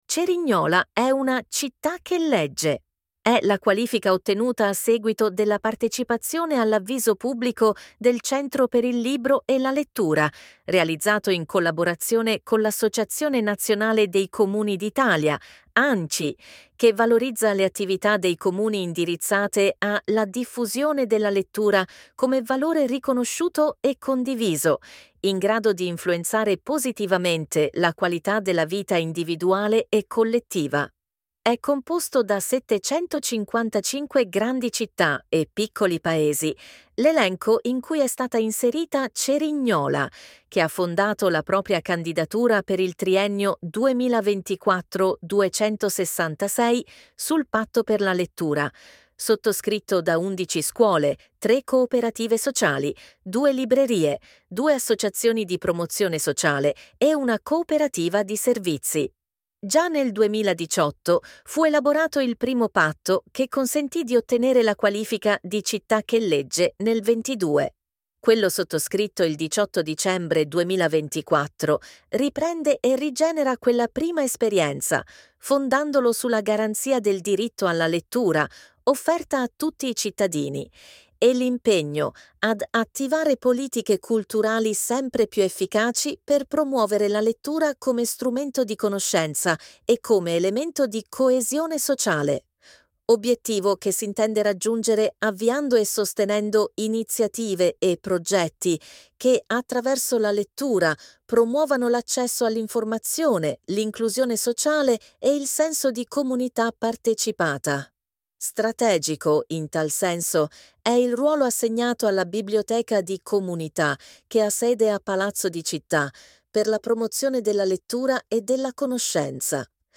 Audiolettura)